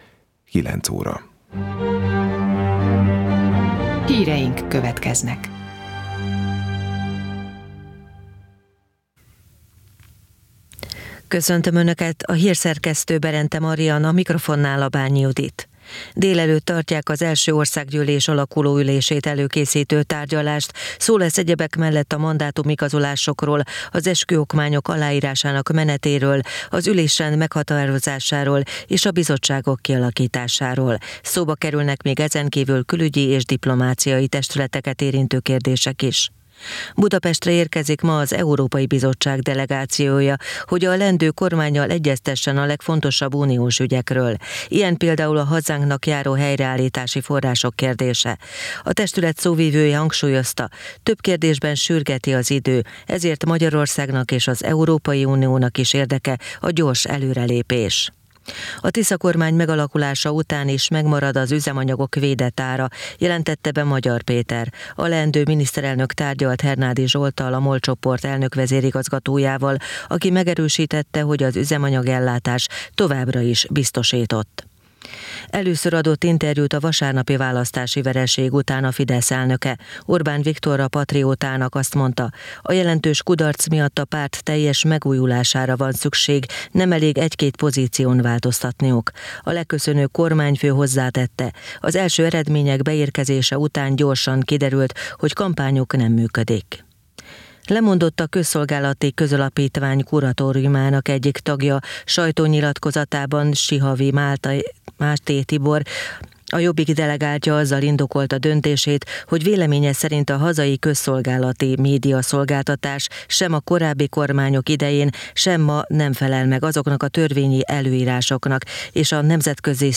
A MÁV Szimfonikus Zenekar Csellóegyüttesének hangversenye
Műsorstruktúrájuk legfőbb komponense a klasszikus zene, kulturális magazinműsorok, világsztárok exkluzív koncertfelvételei, illetve jazz összeállítások.